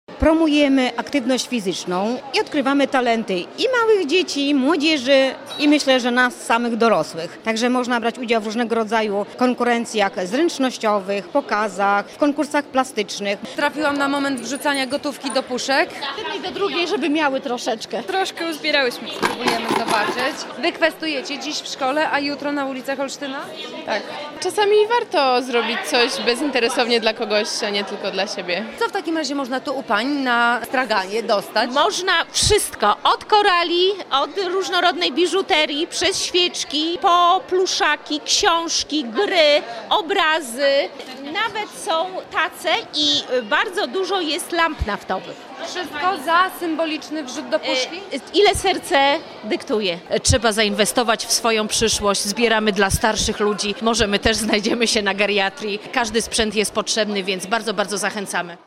Festyn WOŚP w Gimnazjum nr 13 w Olsztynie